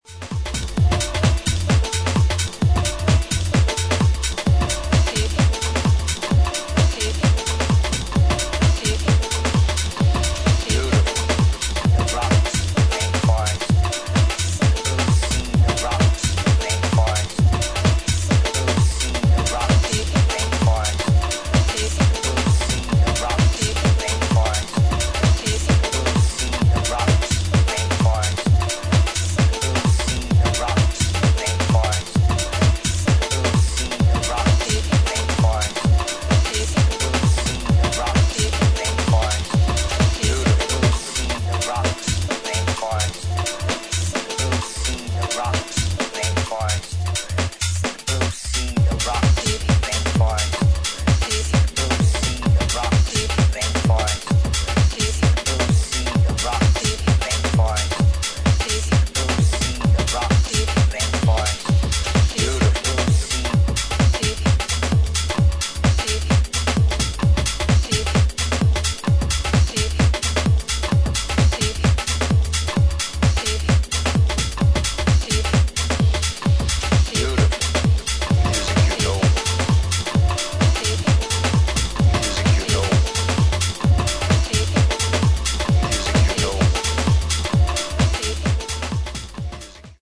ジャジーな要素をアーティストそれぞれが異なる解釈でフィットさせたディープハウス四曲を収録。